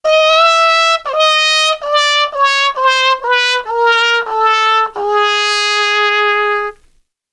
Звук трубы из комедийного фильма